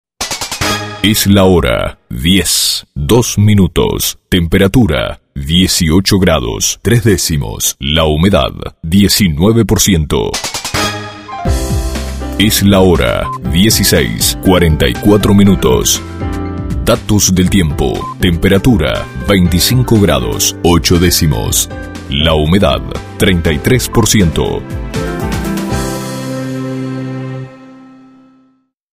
Locuciones de Temperatura y Humedad en off para informar de manera automática los datos del clima.